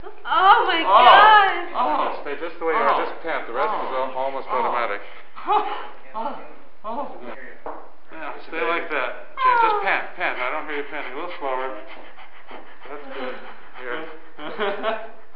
Звук родов американской женщины